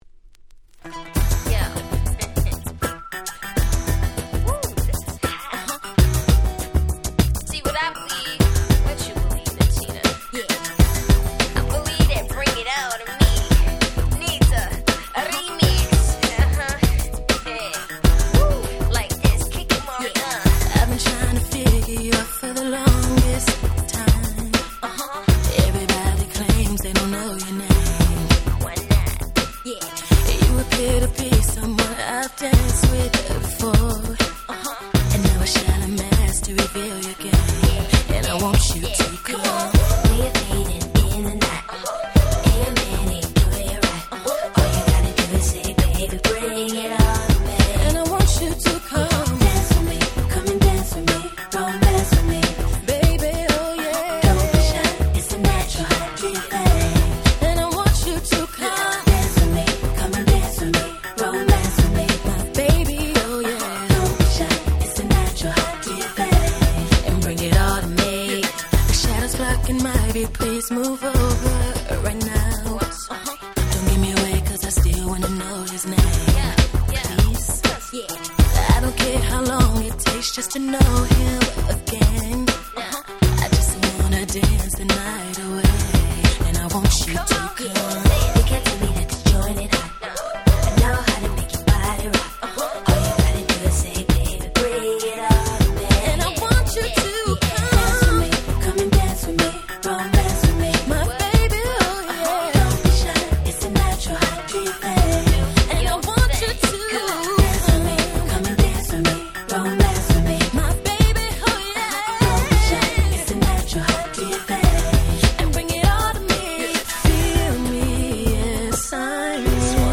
99' Smash Hit R&B